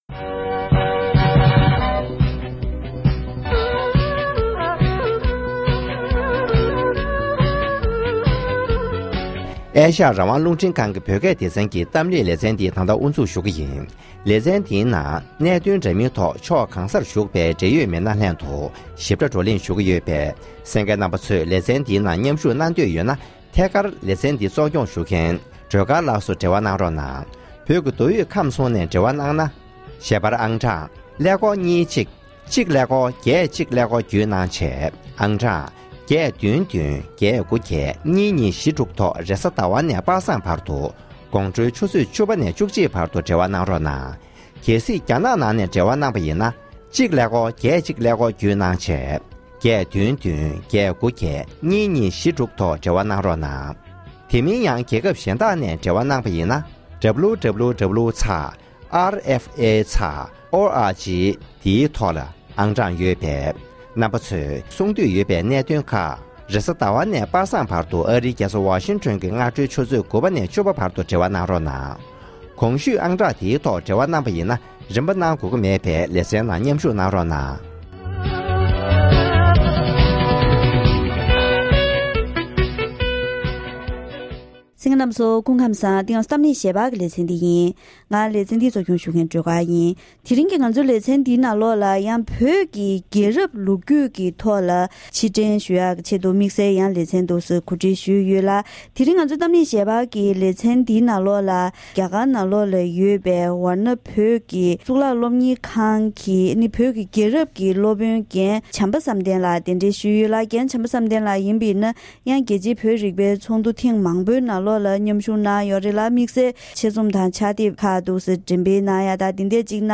༄༅། །ཐེངས་འདིའི་གཏམ་གླེང་ཞལ་པར་ལེ་ཚན་ནང་བོད་ཀྱི་ཆོས་རྒྱལ་སྲོང་བཙན་སྒམ་པོའི་སྐབས་བོད་ས་ཡོངས་རྫོགས་གཅིག་གྱུར་བཟོས་གནང་བ་དང་། བོད་ཡིག་གསར་གཏོད་གནང་བ། ནང་པ་སངས་རྒྱལ་པའི་ཆོས་ལུགས་བོད་དུ་སྲོལ་གཏོད་པ། ཉེ་ཁོར་རྒྱལ་ཁབ་ལ་འབྲེལ་བ་བཙུགས། བོད་ཀྱི་སྤྱི་ཚོགས་ས་བརྟན་ཡོང་ཆེད་ཁྲིམས་དང་སྒྲིག་གཞི་གཏན་འབེབས་གནང་བ་སོགས་བོད་ཀྱི་རྒྱལ་རབས་ལོ་རྒྱུས་ལ་ཕྱིར་དྲན་ཞུས་པ་ཞིག་གསན་རོགས་གནང་།